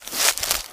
High Quality Footsteps
STEPS Bush, Walk 18.wav